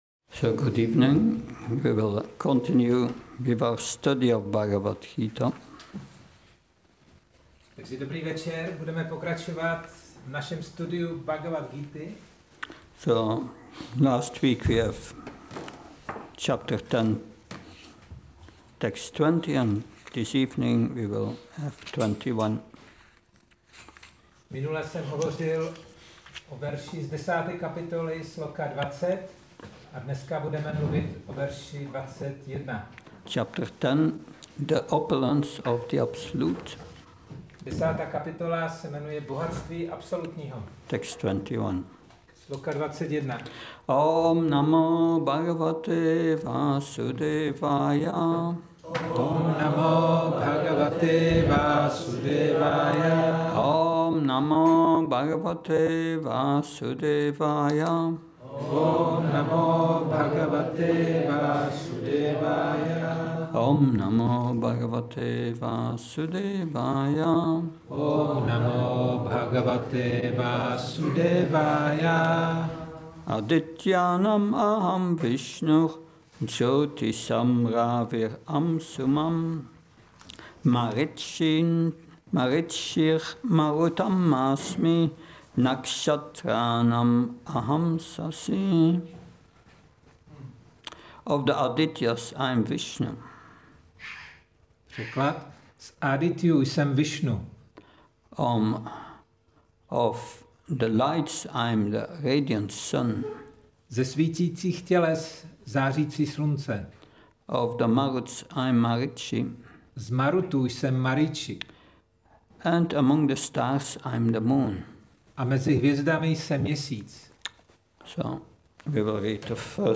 Přednáška BG-10.21 – restaurace Góvinda